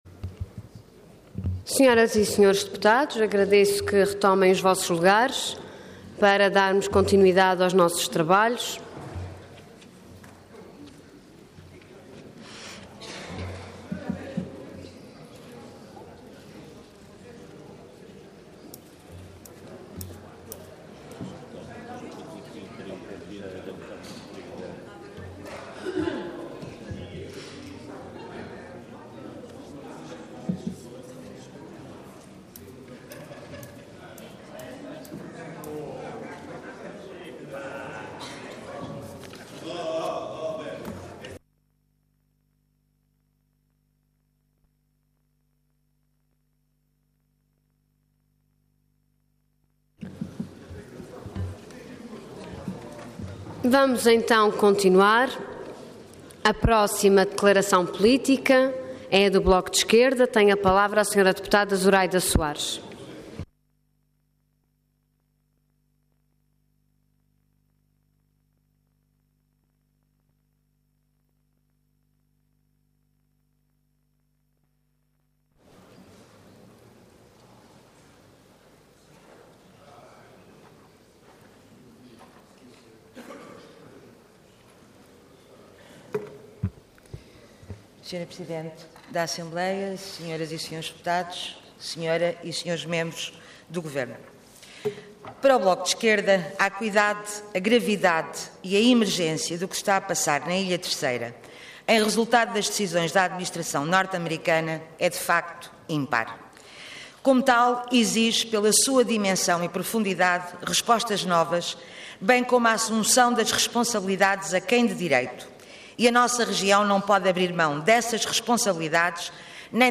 Detalhe de vídeo 11 de fevereiro de 2015 Download áudio Download vídeo Processo X Legislatura Base das Lajes Intervenção Declaração Política Orador Zuraida Soares Cargo Deputada Entidade BE